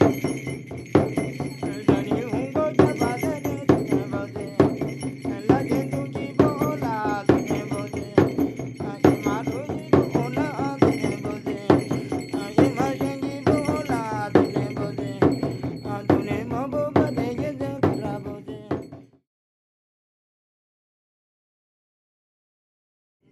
Shaman Ceremonies (3-5)